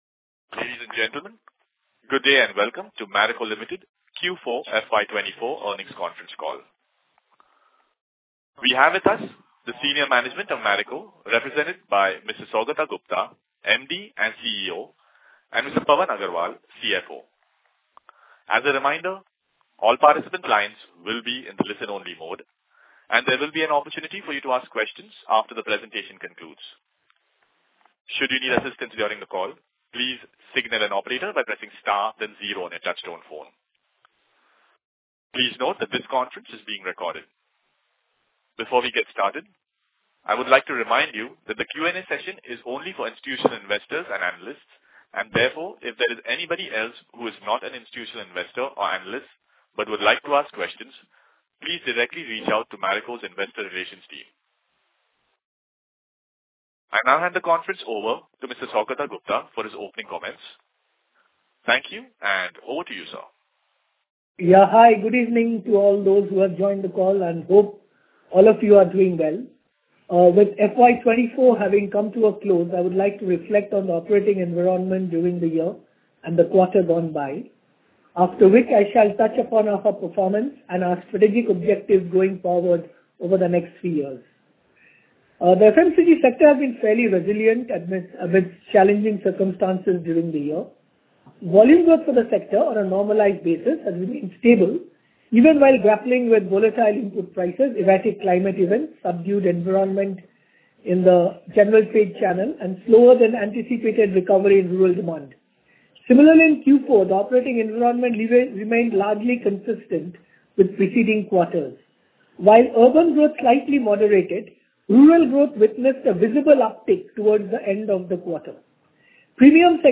Earnings_Call_Recording_Q4FY24.mp3